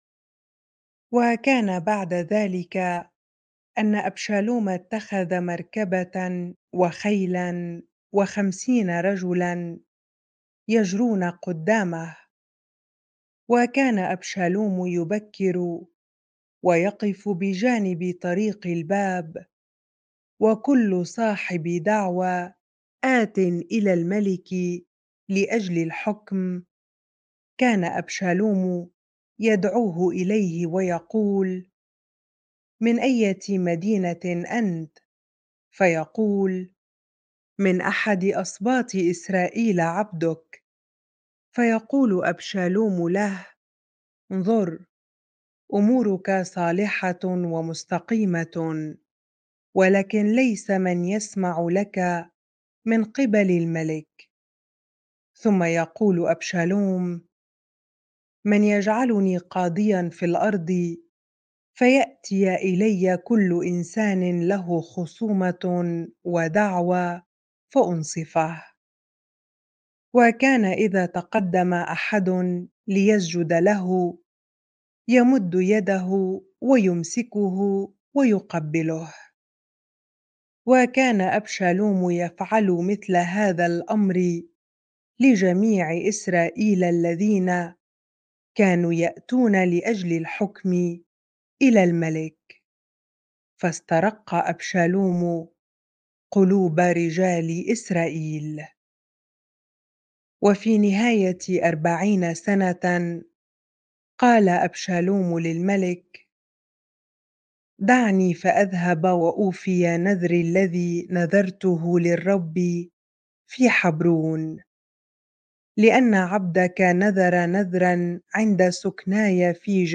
bible-reading-2Samuel 15 ar